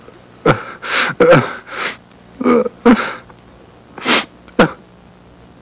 Crying (male) Sound Effect
Download a high-quality crying (male) sound effect.
crying-male-1.mp3